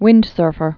(wĭndsûrfər)